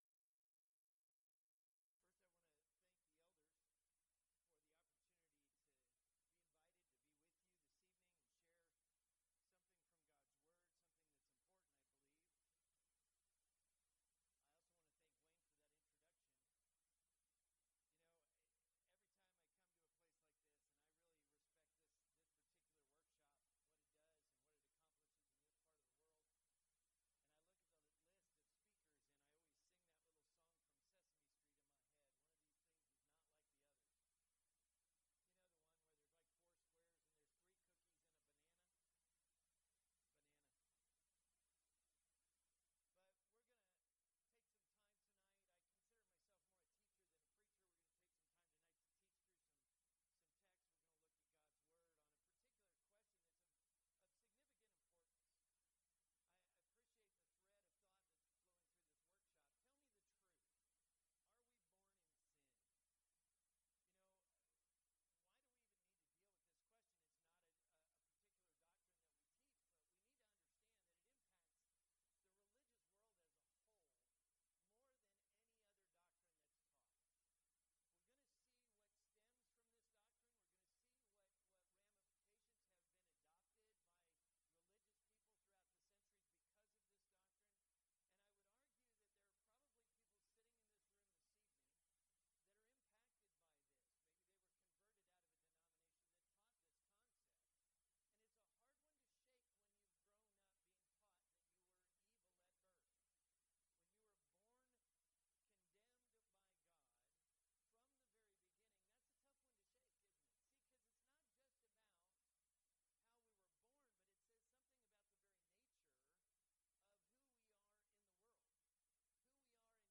Preacher's Workshop
lecture